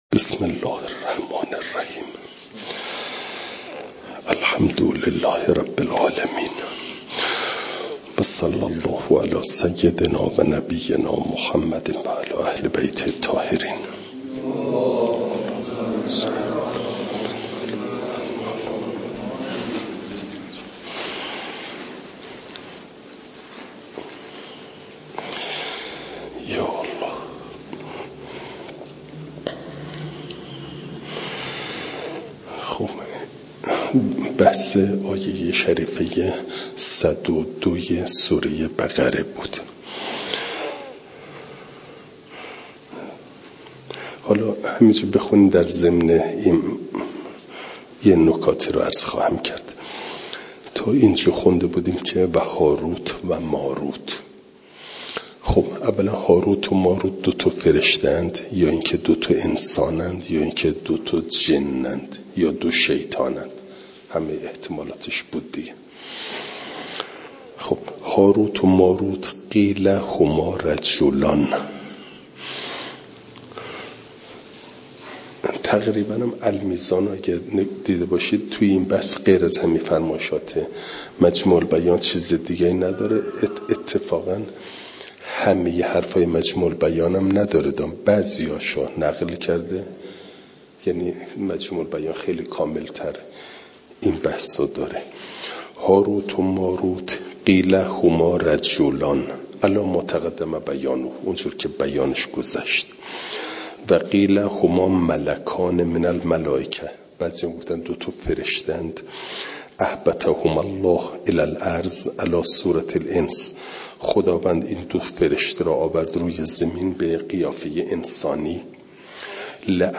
فایل صوتی جلسه صد و ششم درس تفسیر مجمع البیان